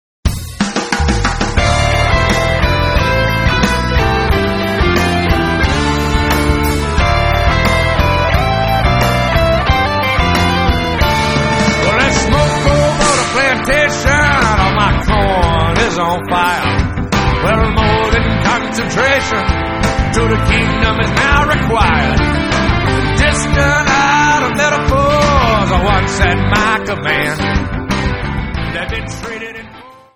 Americana
R&B
Rock
Roots